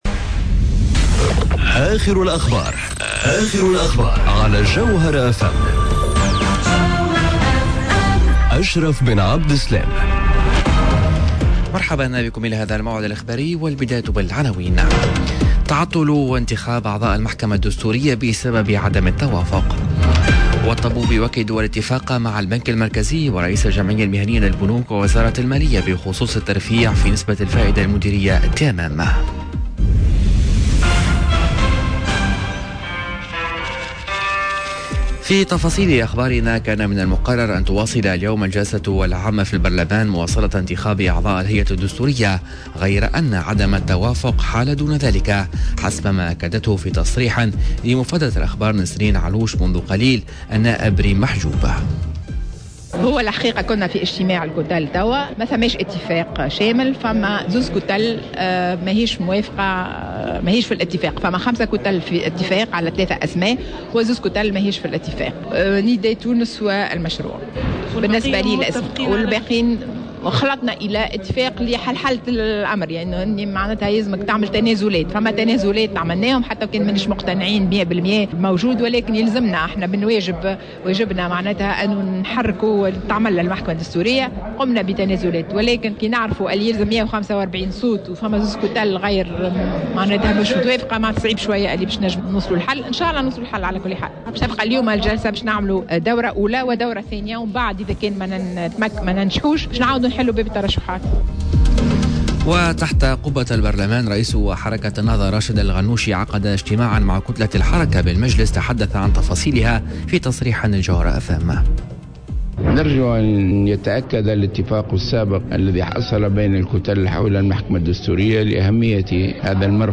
نشرة أخبار منتصف النهار ليوم الإربعاء 06 مارس 2019